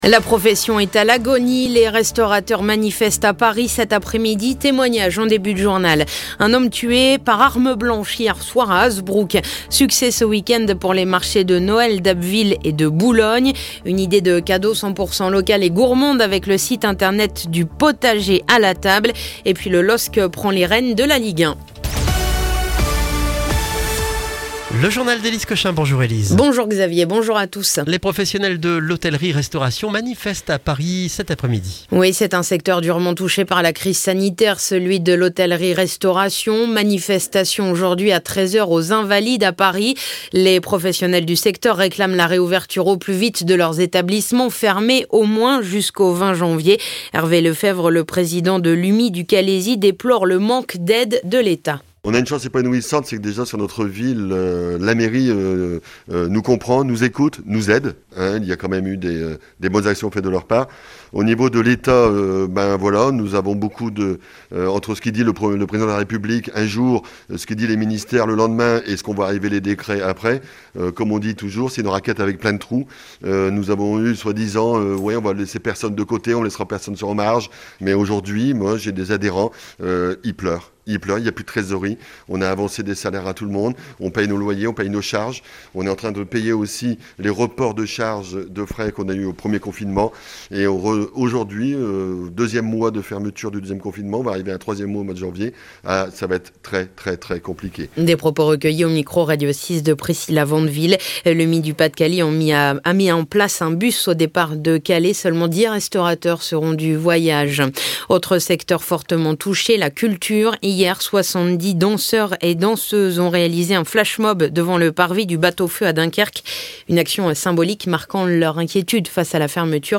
Le journal du lundi 14 décembre